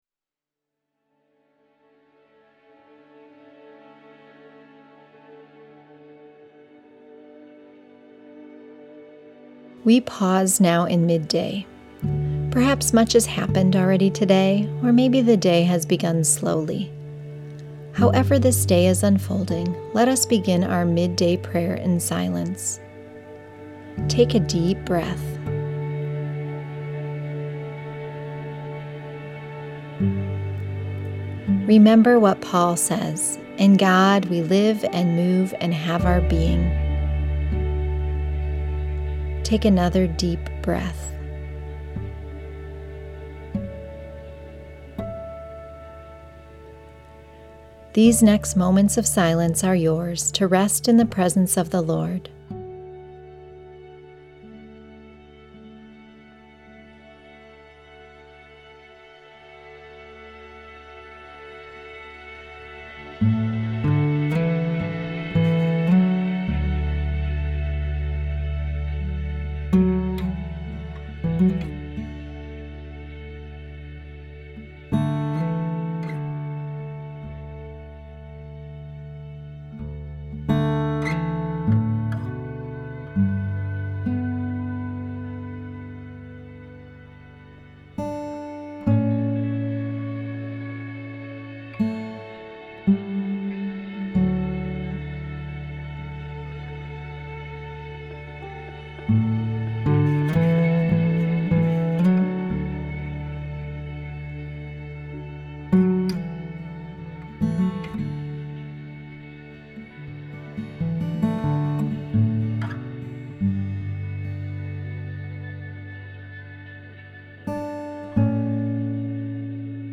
Below you will find a series of guided prayers.
Each prayer begins in silence.